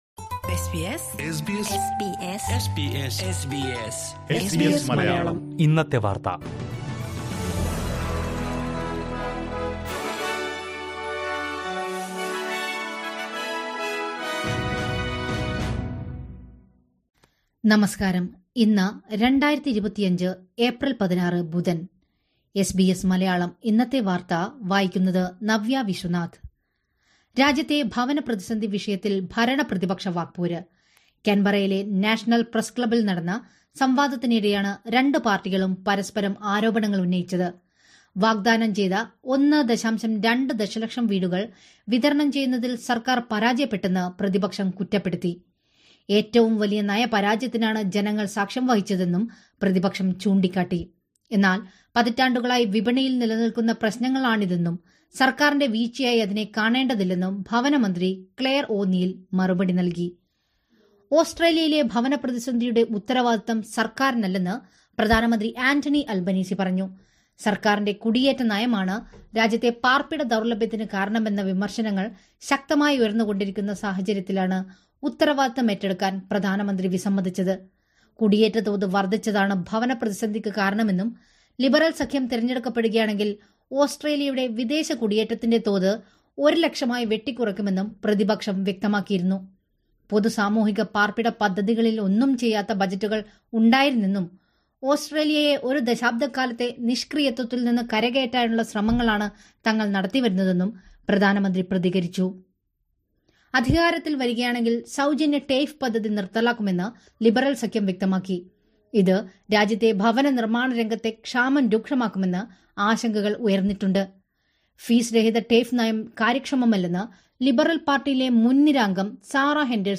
2025 ഏപ്രില്‍ 16ലെ ഓസ്‌ട്രേലിയയിലെ ഏറ്റവും പ്രധാന വാര്‍ത്തകള്‍ കേള്‍ക്കാം...